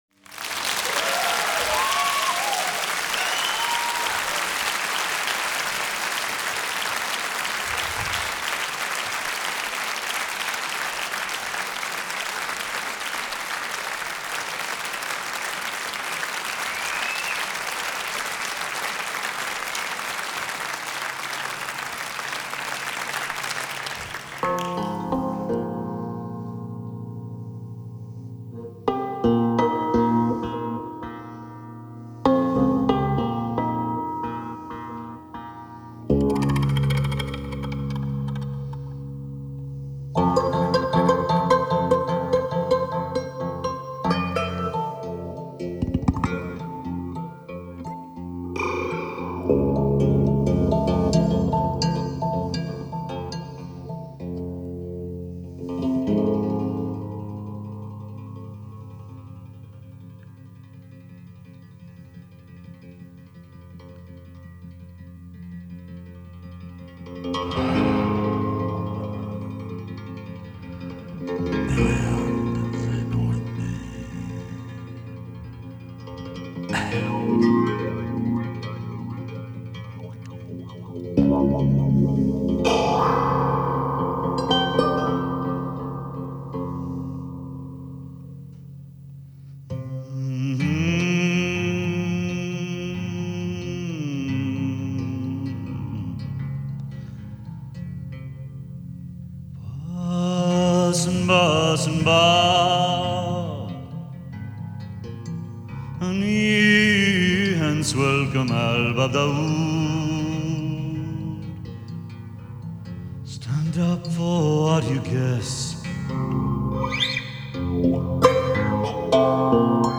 Genre: World Music